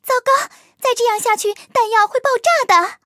卡尔臼炮中破语音.OGG